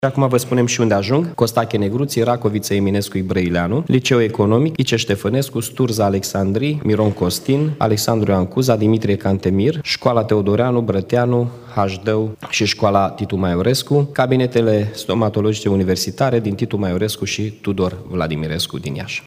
Primarul municipiului Iași, Mihai Chirica